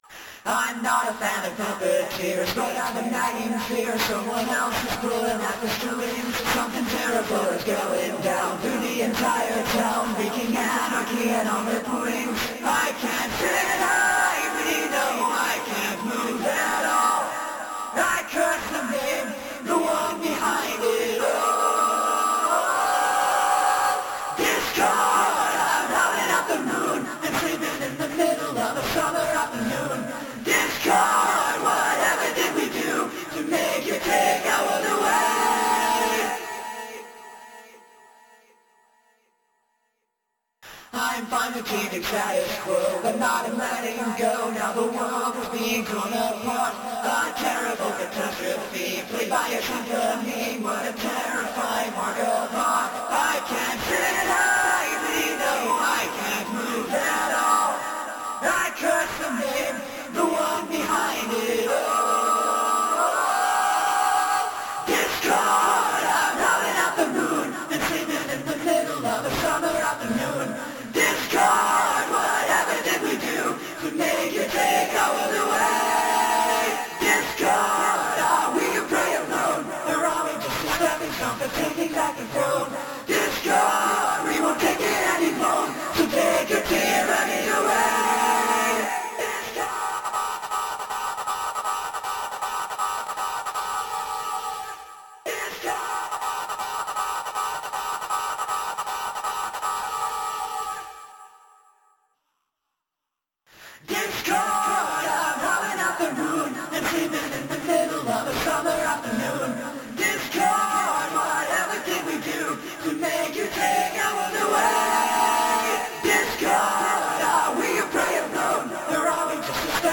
Jevil voice test!